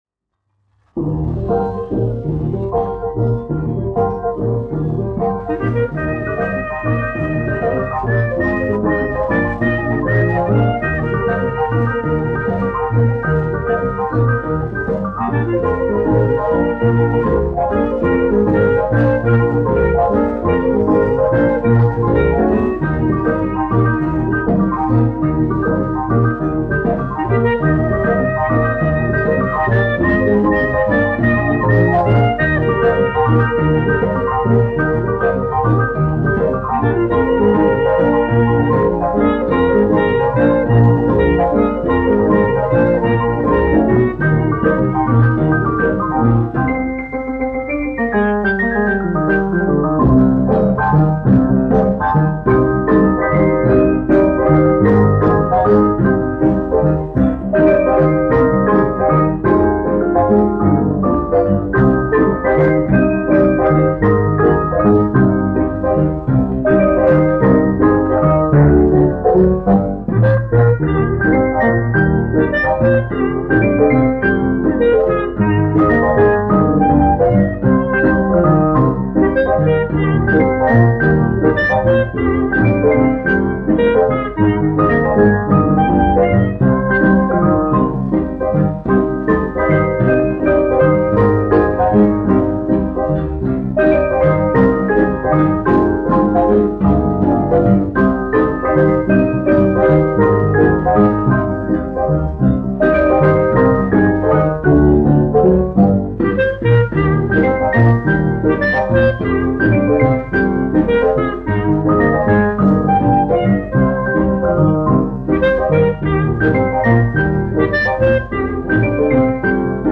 1 skpl. : analogs, 78 apgr/min, mono ; 25 cm
Džezs
Skaņuplate
Latvijas vēsturiskie šellaka skaņuplašu ieraksti (Kolekcija)